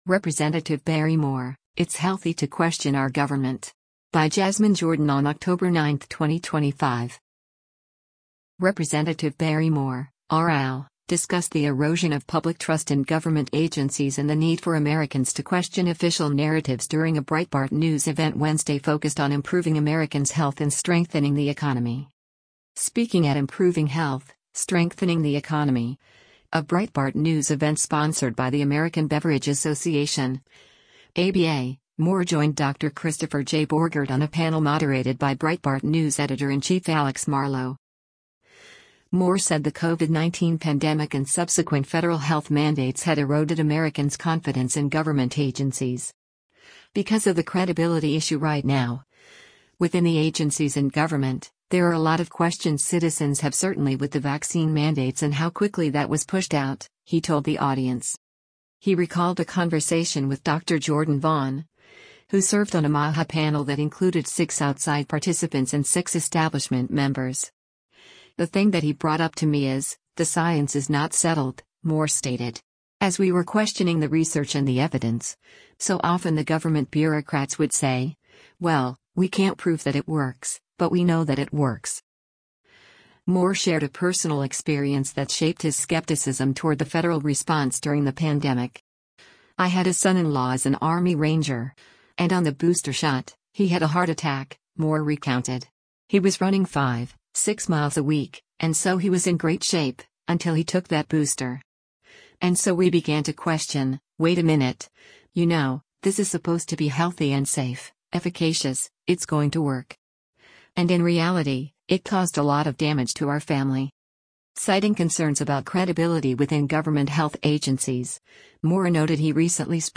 Rep. Barry Moore (R-AL) discussed the erosion of public trust in government agencies and the need for Americans to question official narratives during a Breitbart News event Wednesday focused on improving Americans’ health and strengthening the economy.